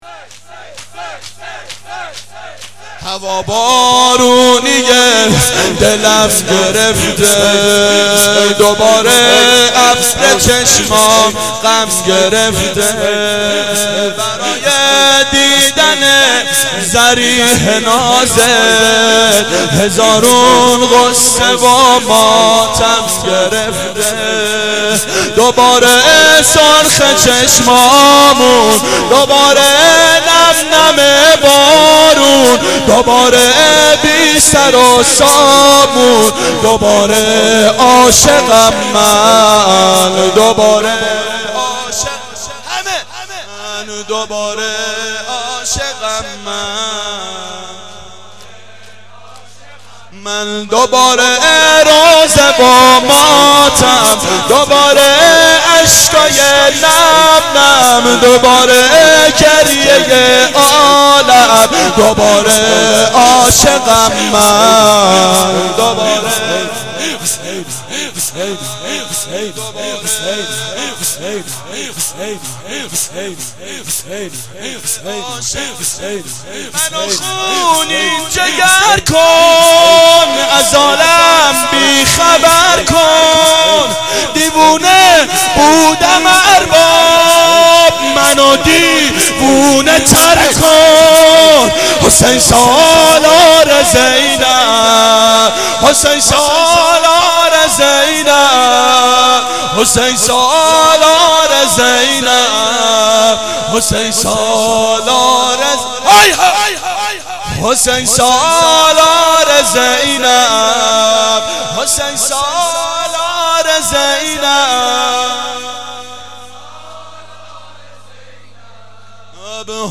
شب اول محرم 89 گلزار شهدای شهر اژیه